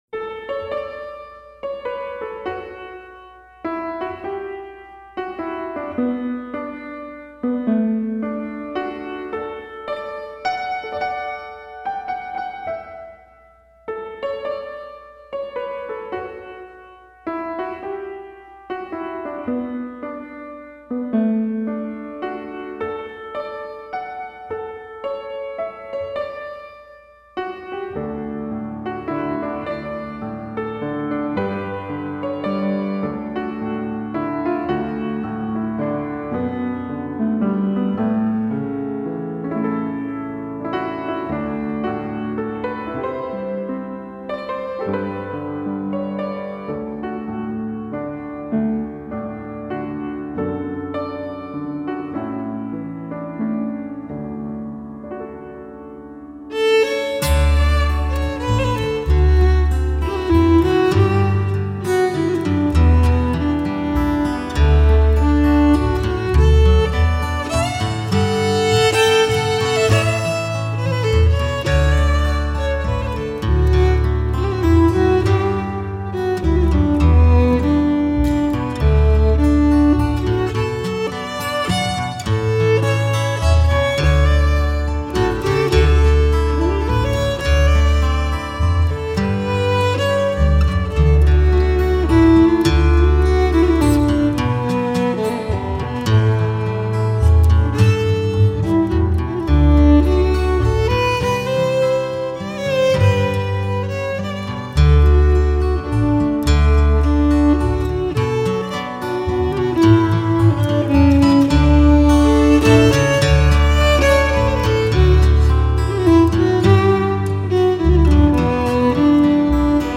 fiddler